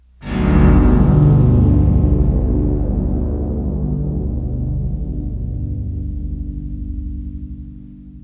screams » scream 60 b
描述：A loud synthesized scary bloodcurdling scream.
标签： atmosphere dark electronic fear howl noise pain processed scream synth voice
声道立体声